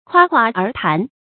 夸夸而談 注音： ㄎㄨㄚ ㄎㄨㄚ ㄦˊ ㄊㄢˊ 讀音讀法： 意思解釋： 形容說話浮夸不切實際。